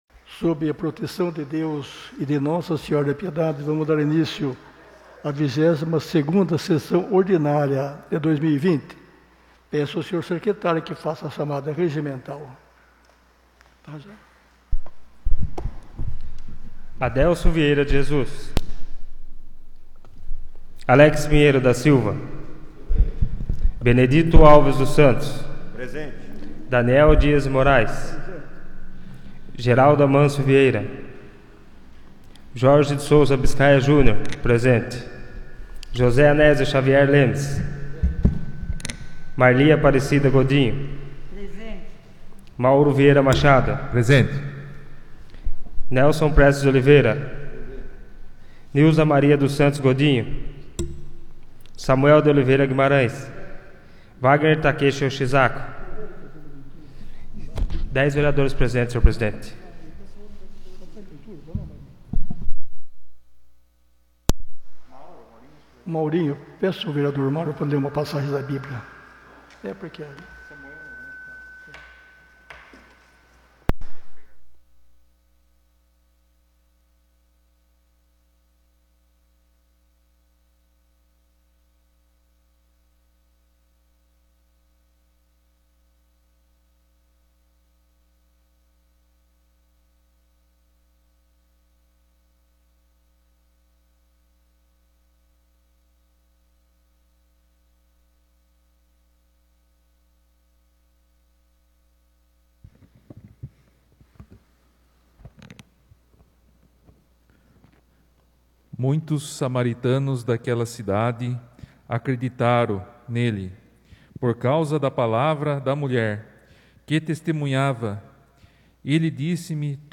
23ª Sessão Ordinária de 2020